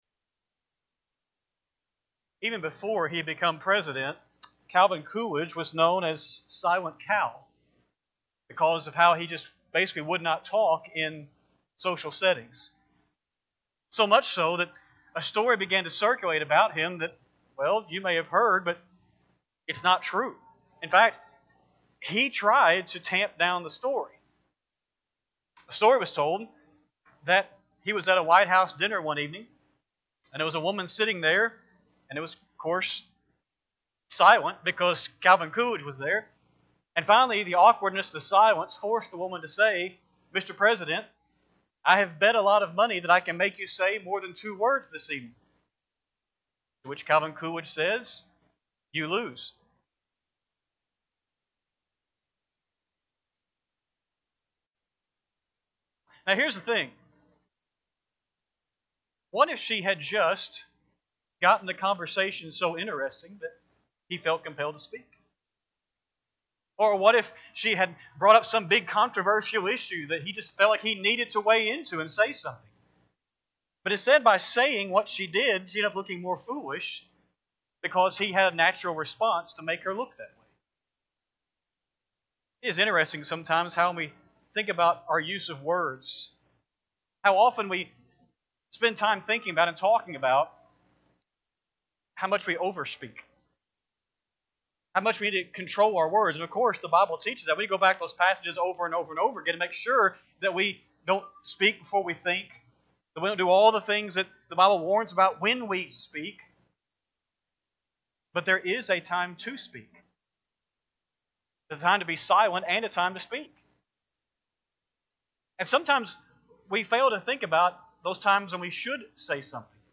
3-22-26-Sunday-AM-Sermon.mp3